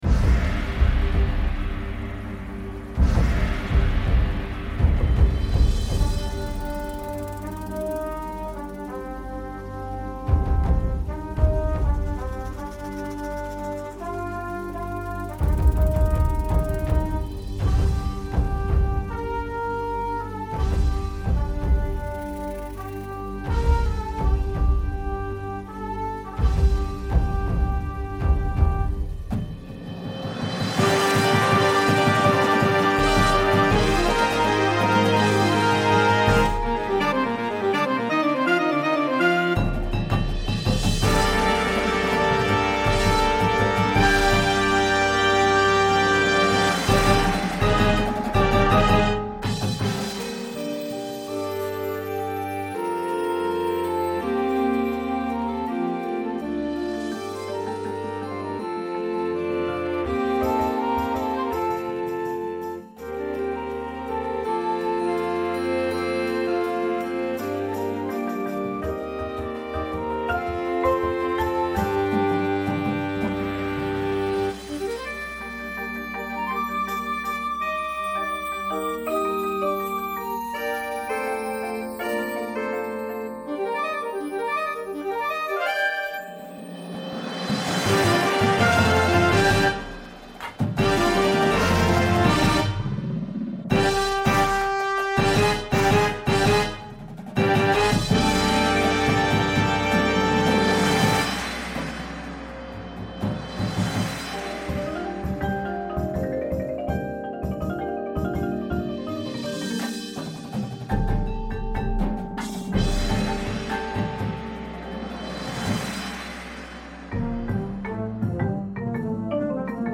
• Flute
• Clarinet 1, 2
• Alto Sax 1, 2
• Trumpet 1
• Horn in F
• Trombone 1, 2
• Tuba
• Snare Drum
• Bass Drums
• Front Ensemble